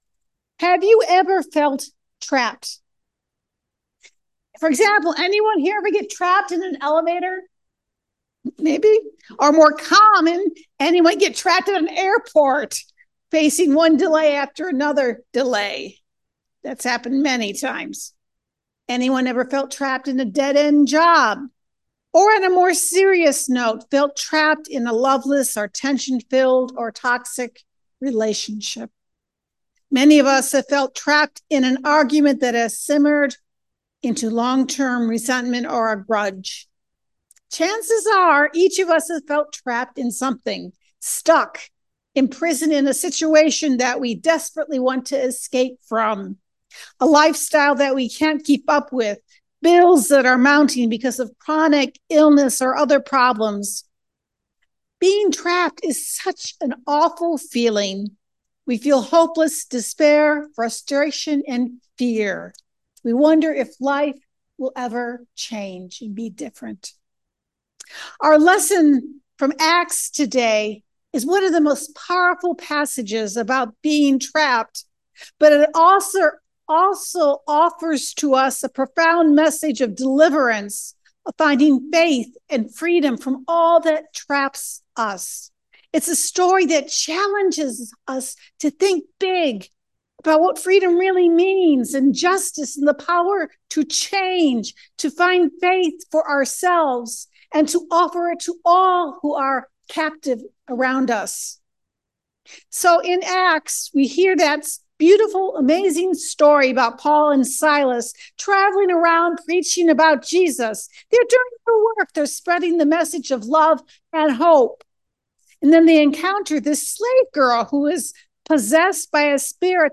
Service Type: Sunday Morning Gospel reading